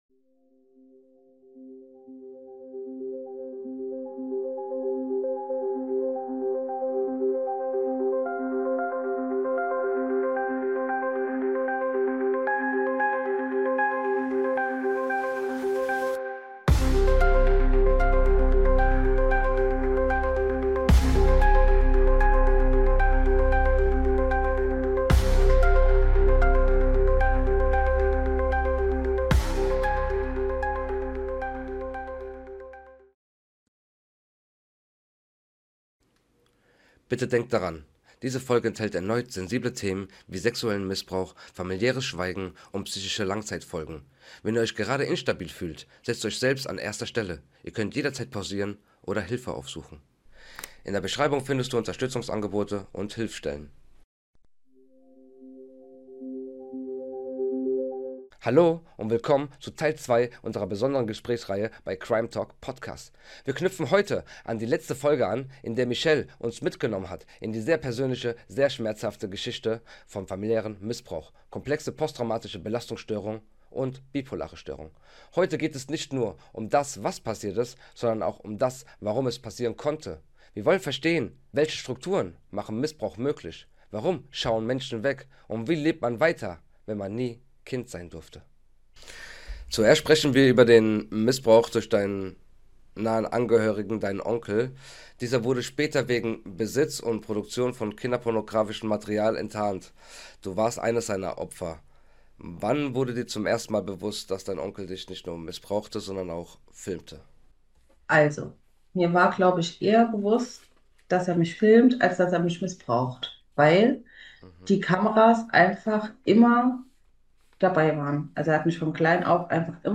Ein mutiges Gespräch.